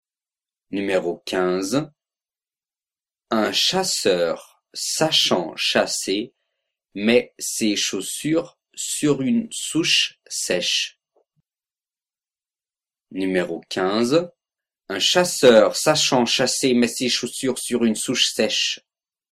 15 Virelangue